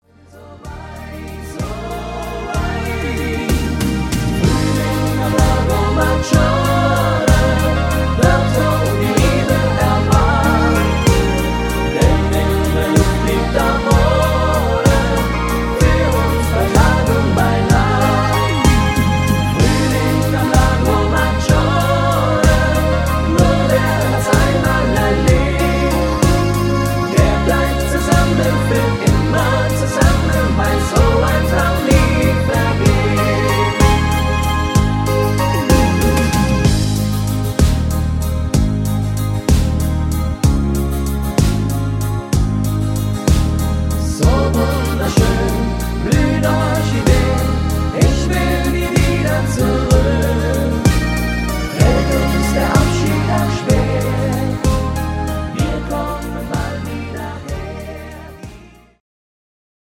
Rhythmus  Waltz
Art  Deutsch, Volkstümlicher Schlager